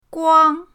guang1.mp3